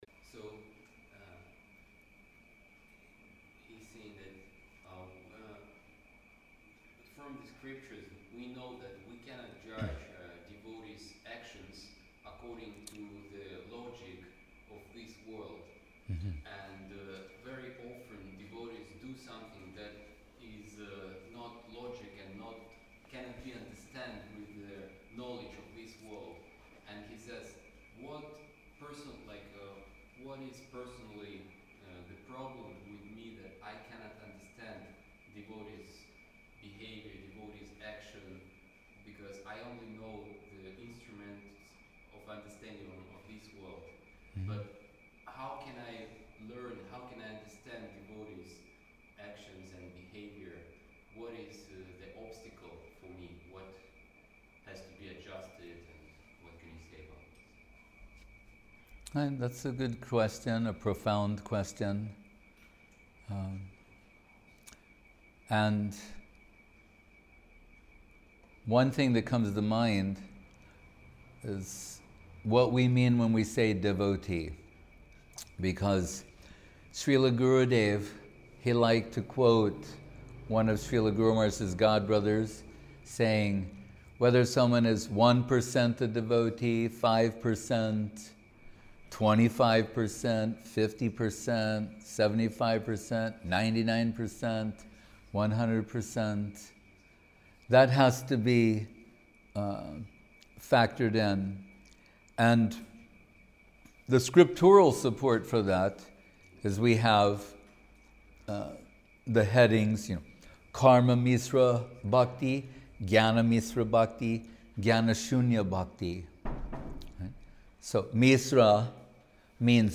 Place: Gupta Govardhan Chiang Mai